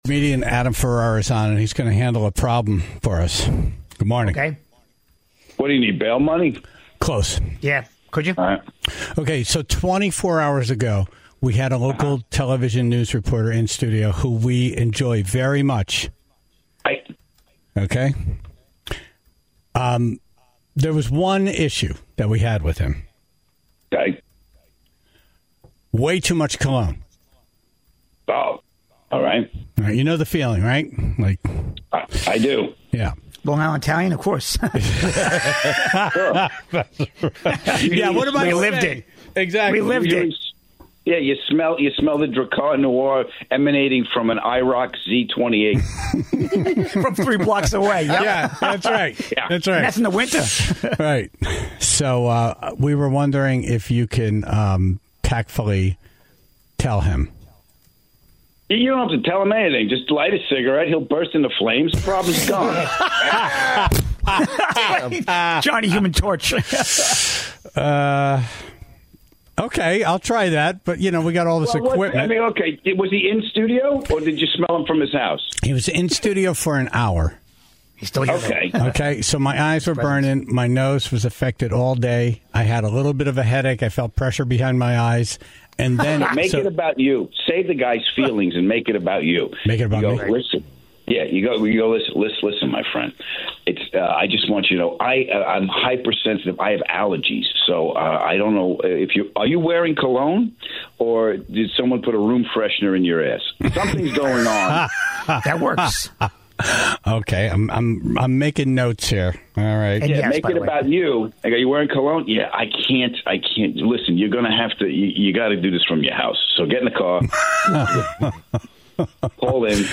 Comedian Adam Ferrara was on the phone